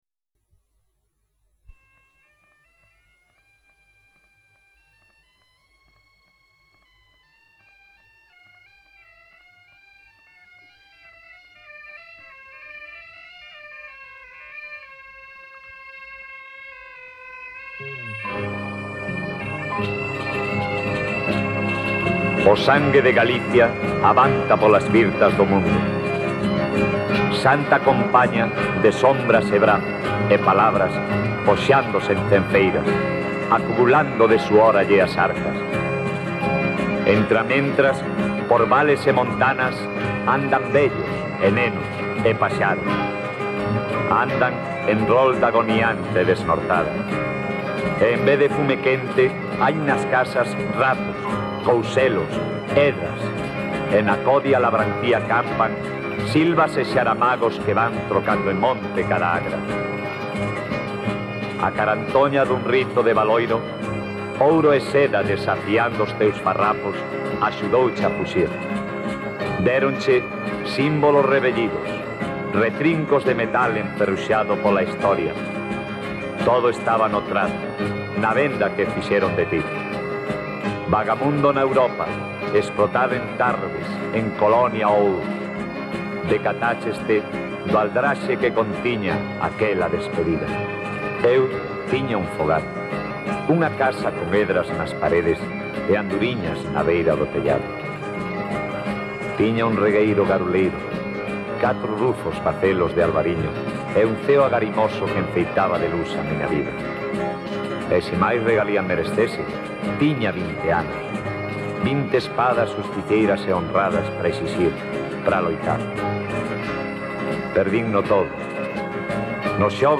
NA Música: Popular Voz